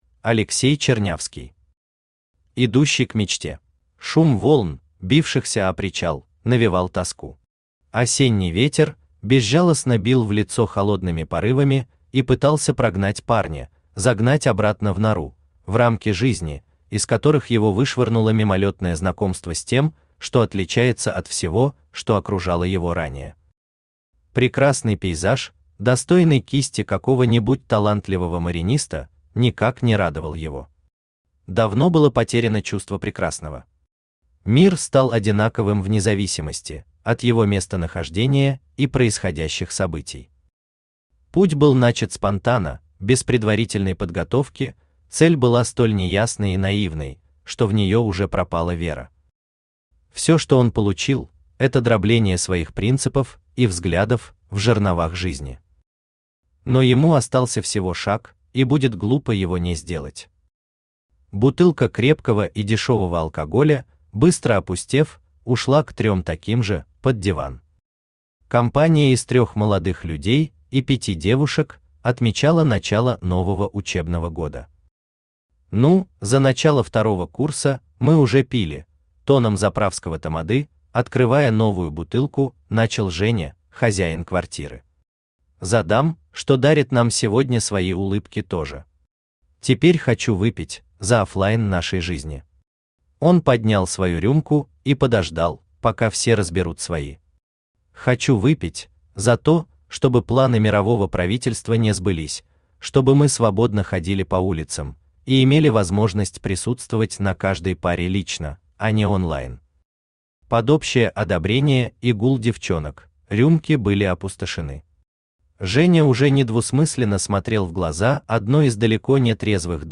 Aудиокнига Идущий к мечте Автор Алексей Андреевич Чернявский Читает аудиокнигу Авточтец ЛитРес.